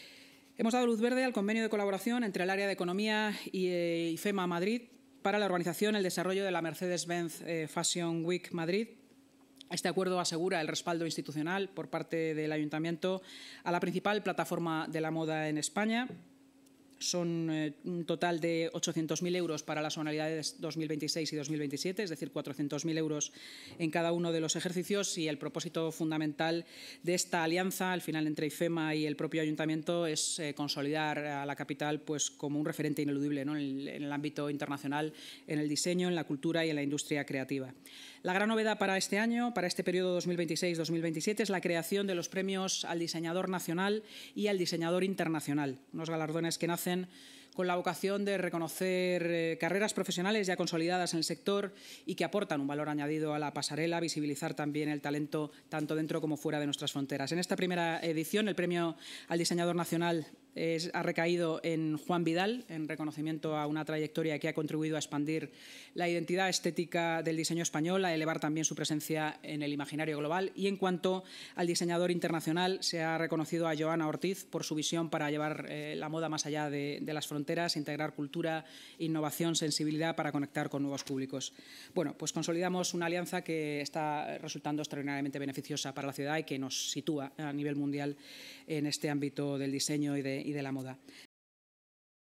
La Junta de Gobierno ha dado luz verde al convenio de colaboración entre el Área de Economía, Innovación y Hacienda e IFEMA Madrid para la organización y desarrollo de Mercedes-Benz Fashion Week Madrid (MBFWM), según ha explicado la vicealcaldesa y portavoz municipal, Inma Sanz, en rueda de prensa.